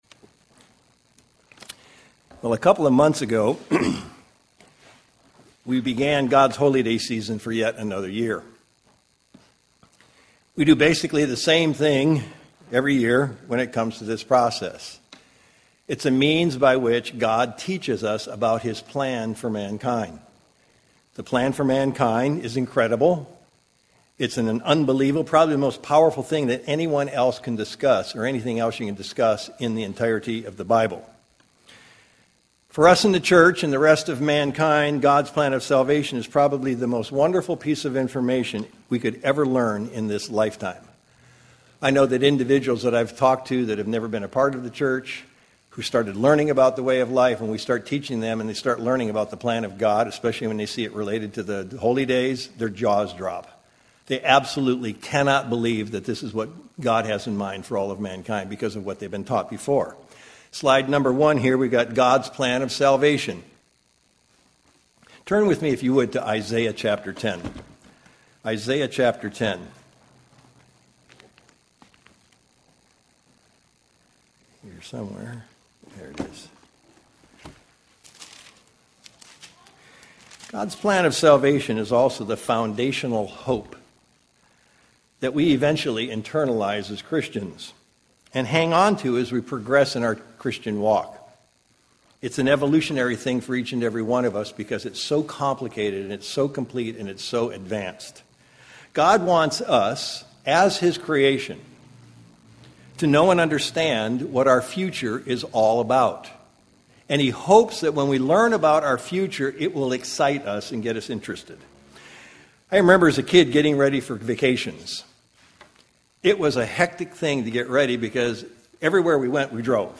Sermons
Given in Sacramento, CA